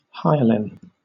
Ääntäminen
Southern England: IPA : /ˈhaɪəlɪn/